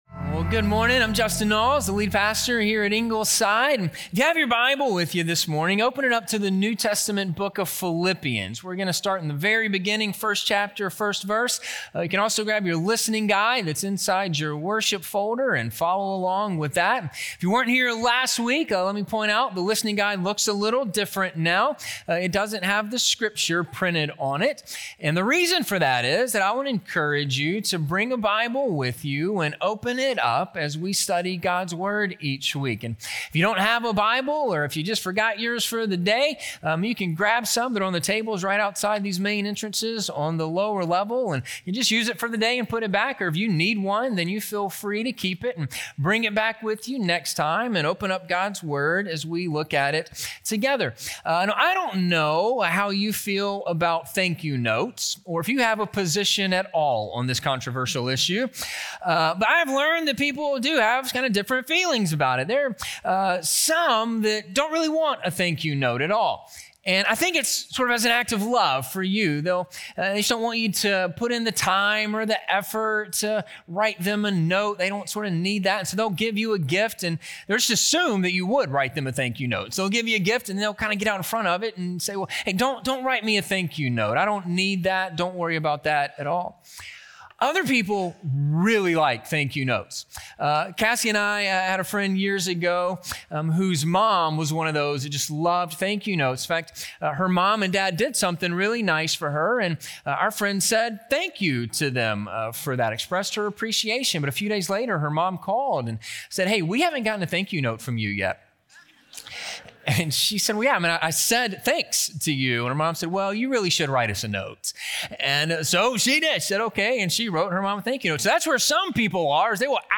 A Partnership and a Prayer - Sermon - Ingleside Baptist Church